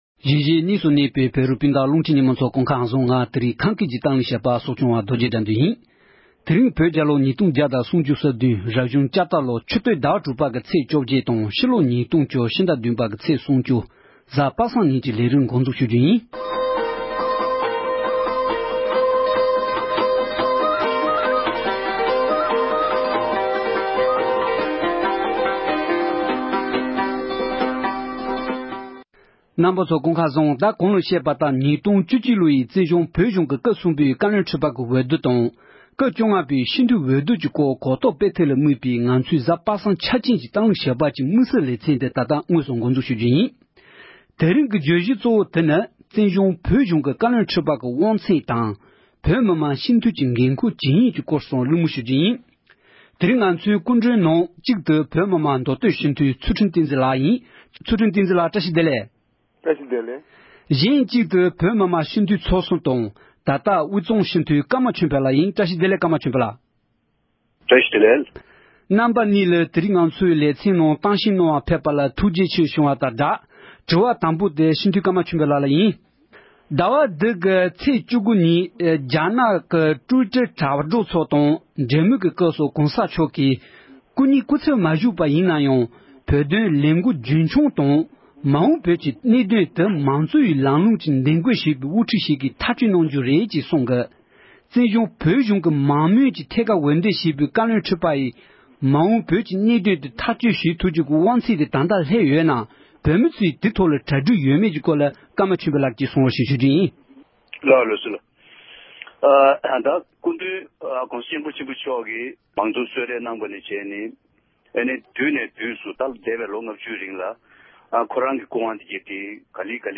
༄༅༎དེ་རིང་གི་དམིགས་བསལ་གྱི་གཏམ་གླེང་ཞལ་པར་ཞེས་པའི་ལེ་ཚན་ནང་དུ།